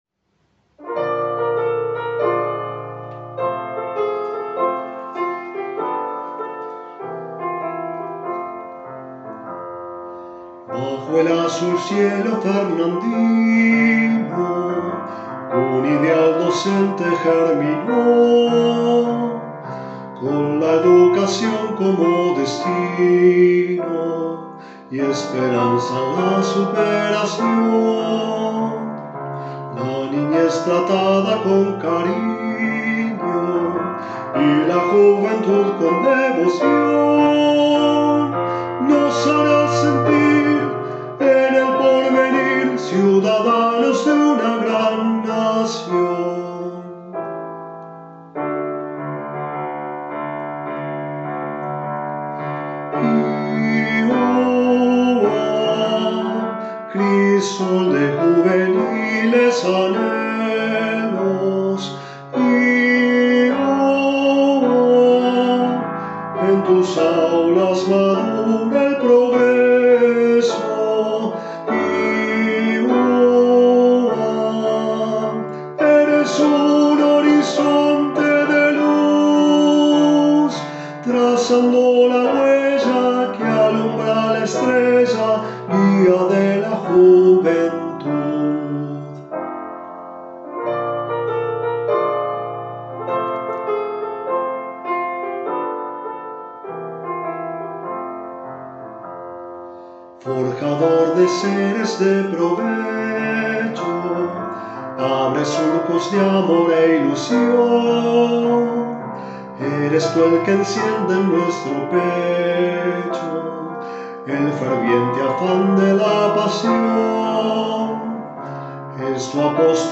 voz y piano